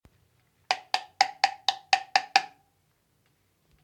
Golpeo con varilla
Grabación sonora que capta el sonido del golpeo de una varilla, de madera o plástico, sobre una superficie (posiblemente el borde de un recipiente, hoya o similar).
Sonidos: Acciones humanas
Sonidos: Hogar